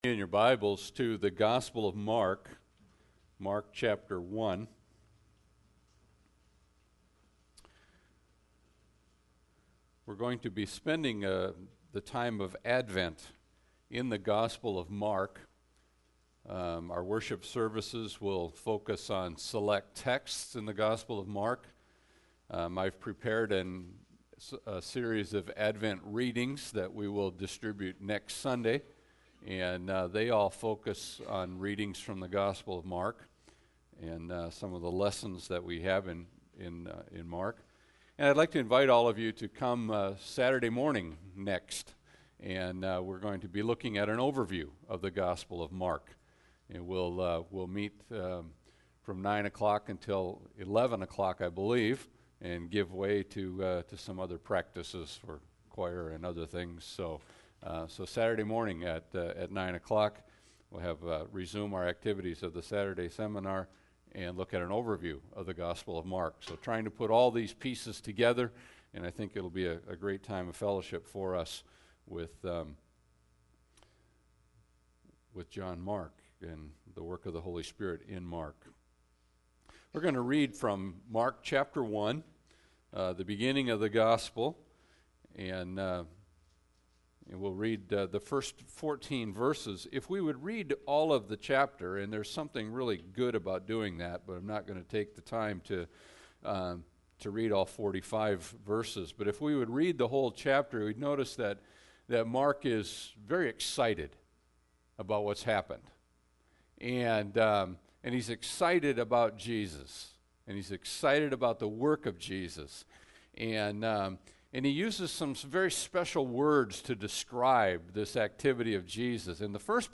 Passage: Mark 1:1-14 Service Type: Sunday Service